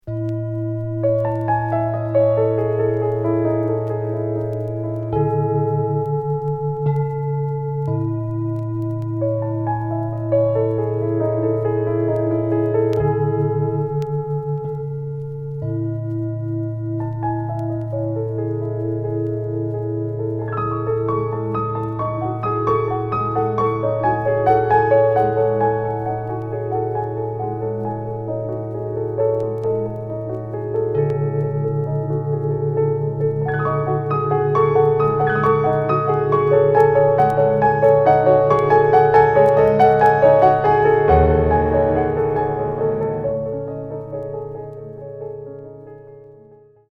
即興